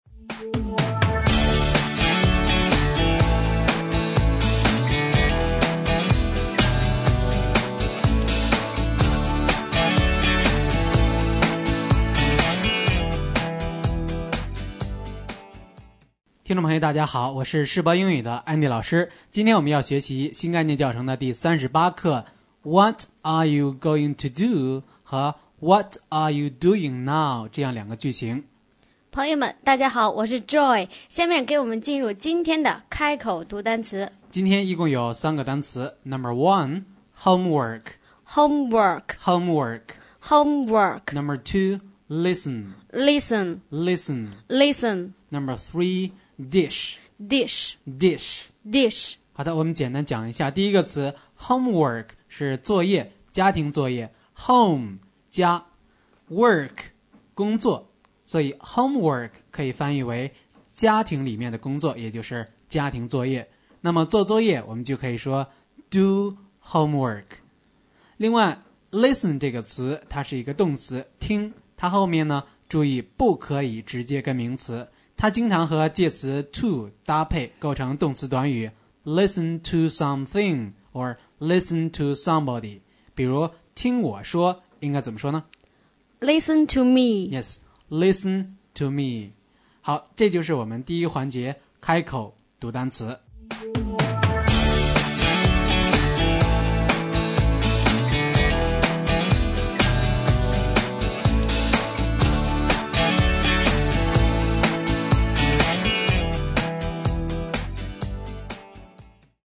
新概念英语第一册第38课【开口读单词】